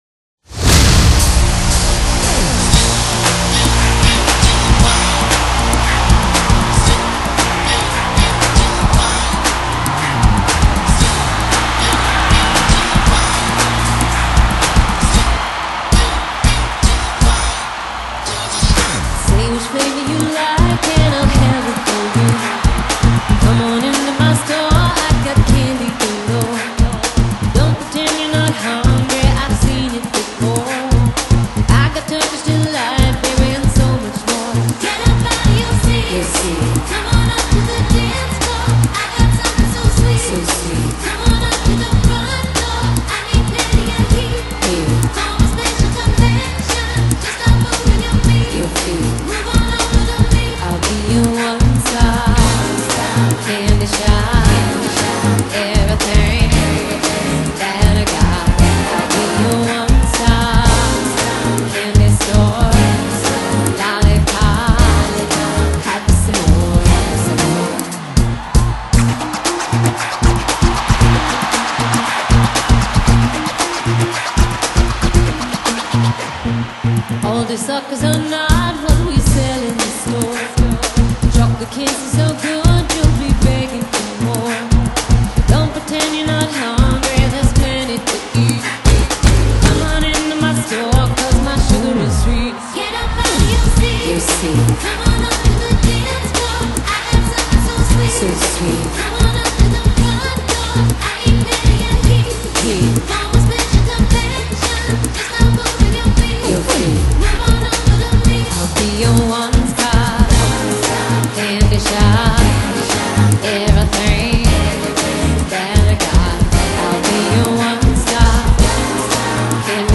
長達62分鐘的現場實況錄音CD ，完美重現2008年12月阿根廷布宜諾斯艾利斯的精彩演出實況！